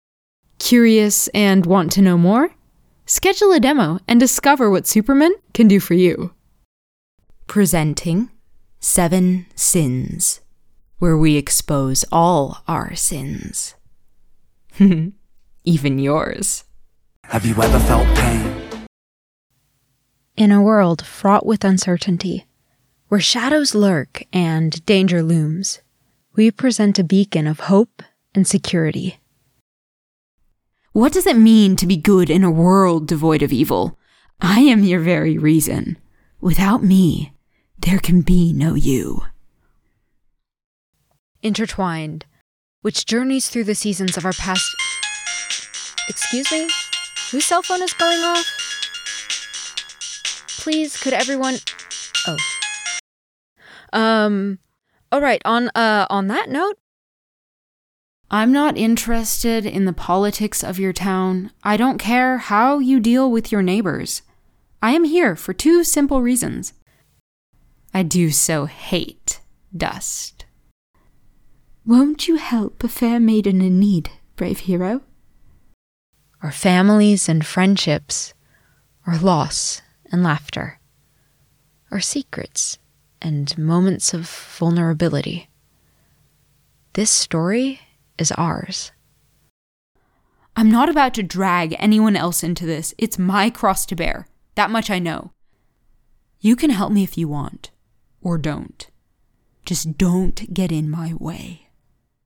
Voice Reel
A mixed demo reel containing snippets of various voice overs, including those created for various clients, auditions, and other texts. This contains examples of both commercial voice-over and character acting.